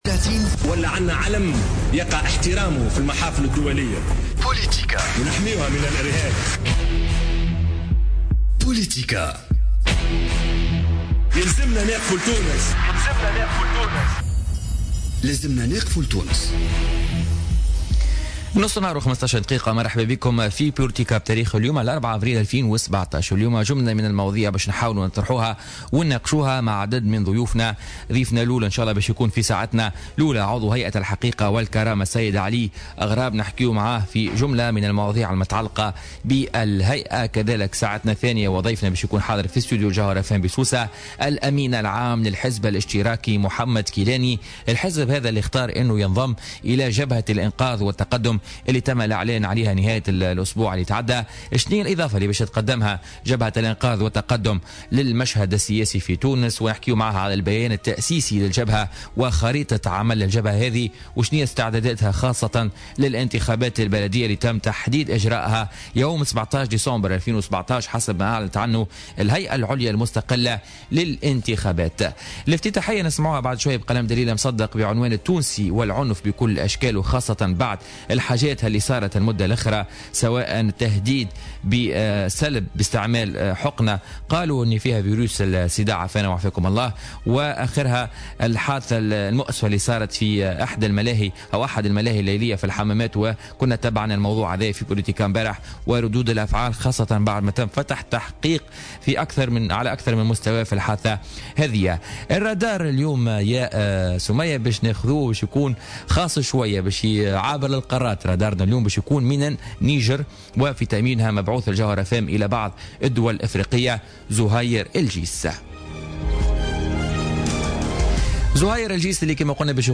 Mohamed Kilani, invité de Politica